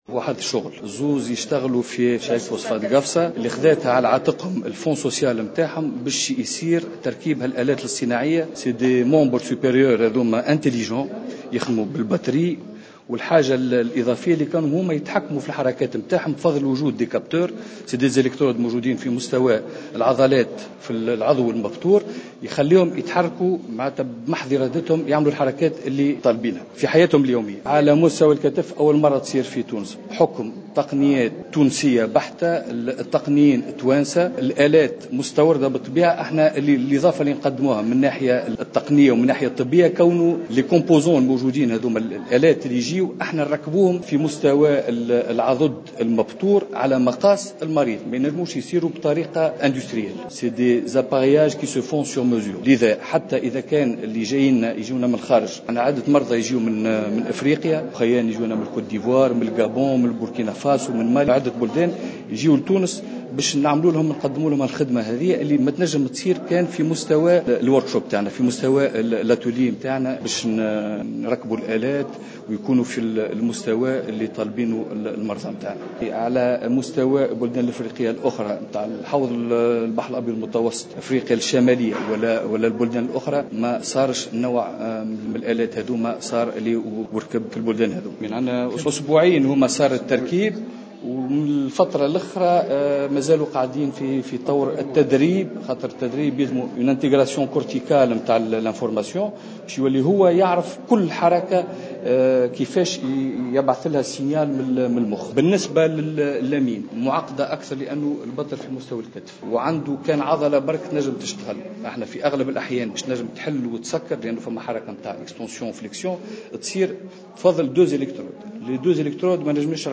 على هامش ملتقى السياحة الطبية الذي انعقد اليوم الجمعة 22 ماي 2015 بالحمامات